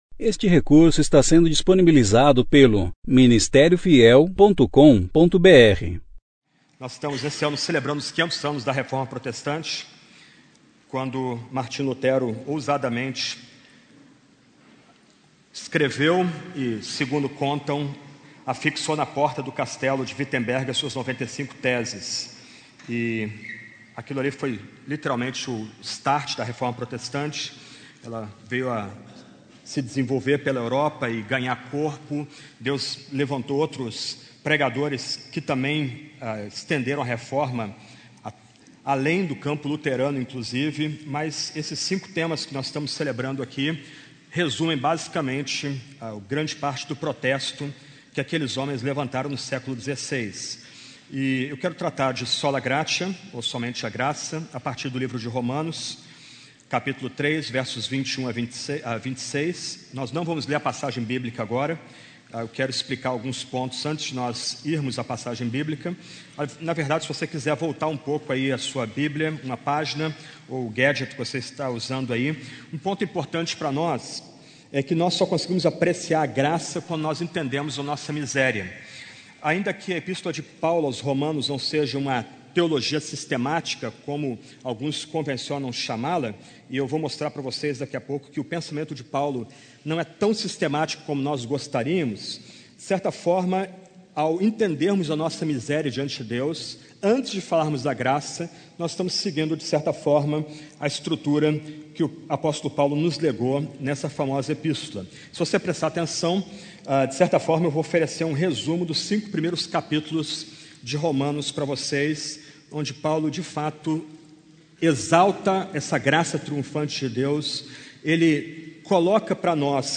Conferência: 15ª Conferência Fiel para Jovens – Brasil Tema: 5 Solas Ano: 2017 Mensagem: Sola Gratia